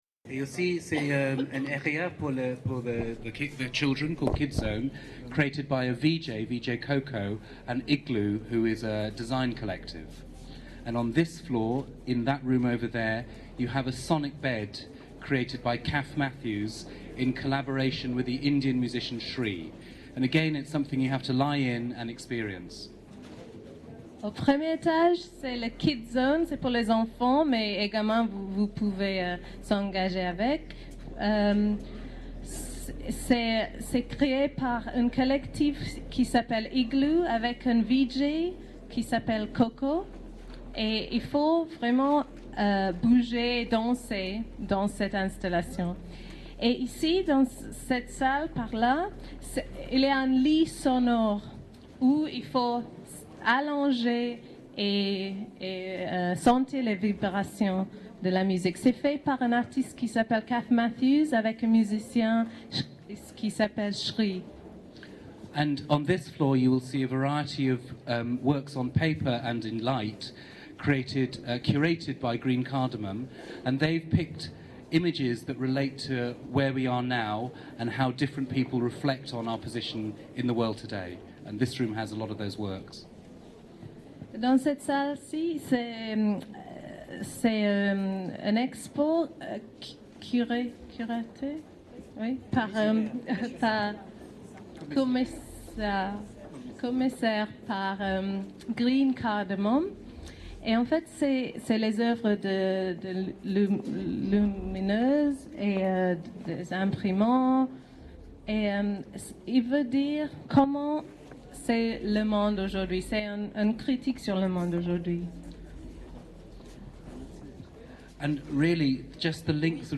Présentation générale